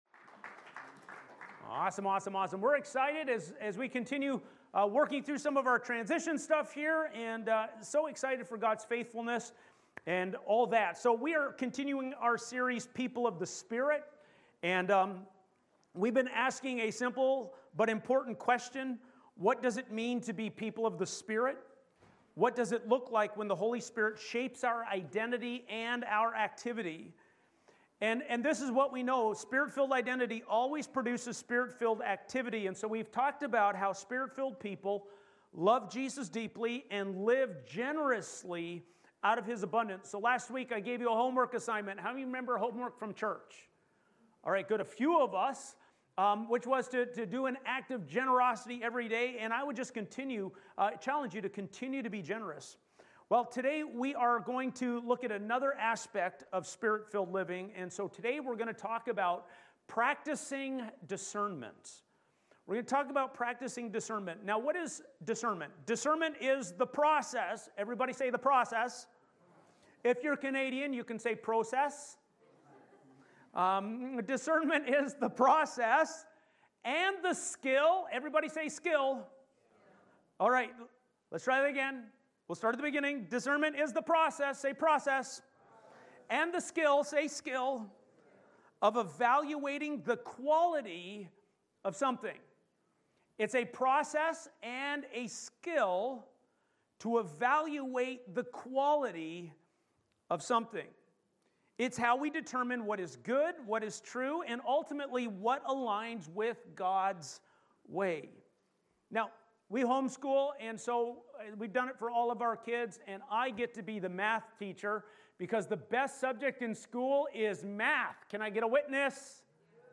Sermons | Asbury Church